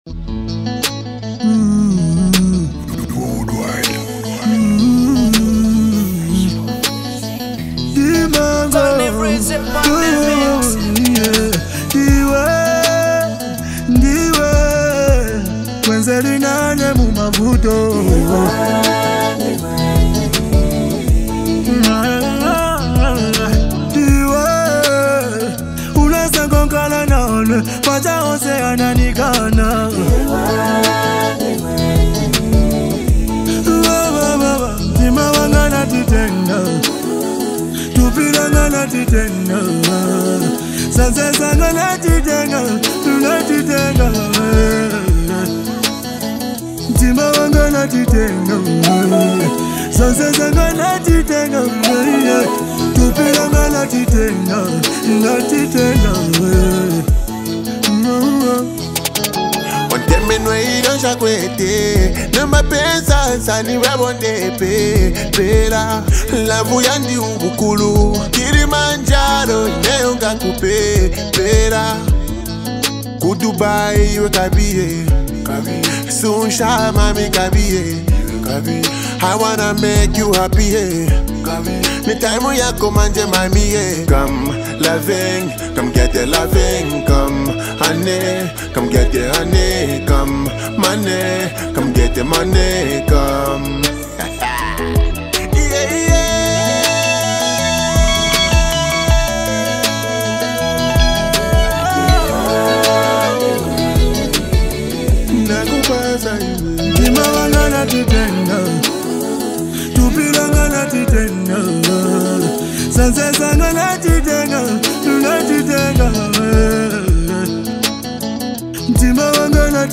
Enjoy this beautiful love song today!